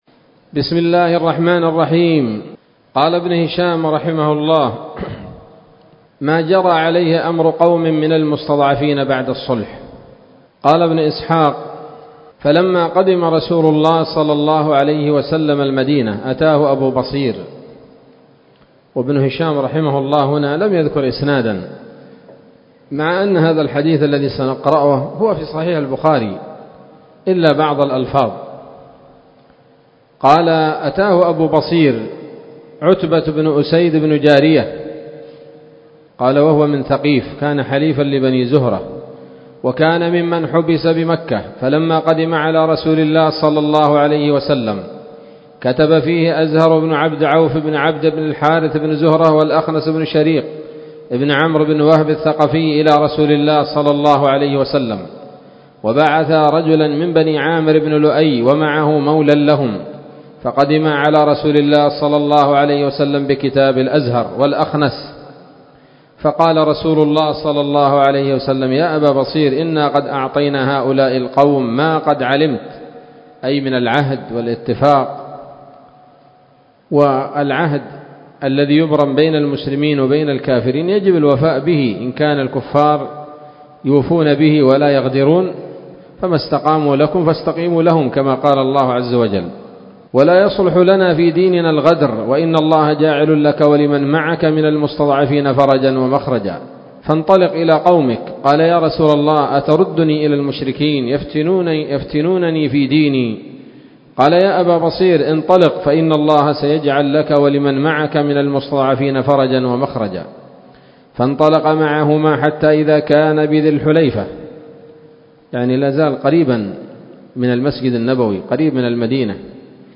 الدرس السادس والثلاثون بعد المائتين من التعليق على كتاب السيرة النبوية لابن هشام